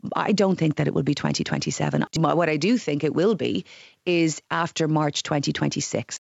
The Labour Party believes it could be as far out as 2027, but Jennifer Carroll MacNeill doesn’t agree: